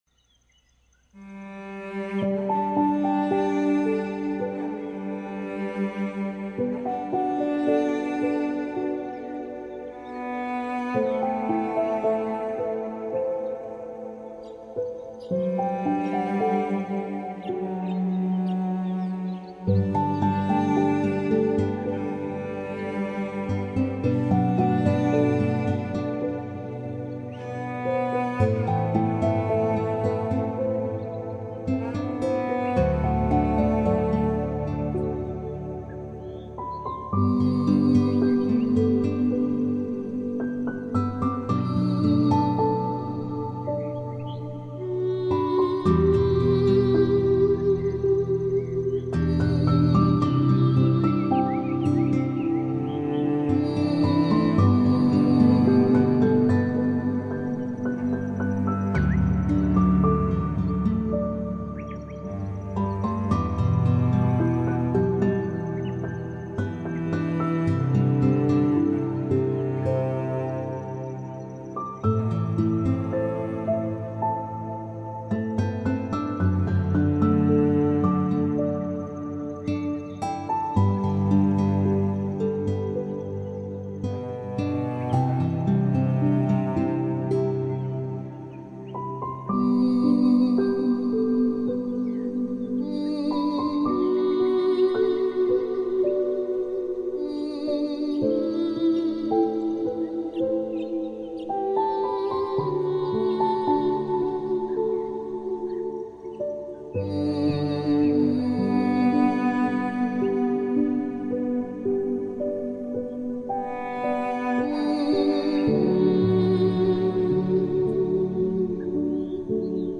Purple Skies | Ocean Ambience for Deep Sleep Escape
Each episode of Relaxing Sounds is filled with soft ambient audio—gentle ocean waves, forest breeze, crackling fireplaces, Tibetan bowls, wind chimes, flowing water, and other calming environments that bring you closer to nature.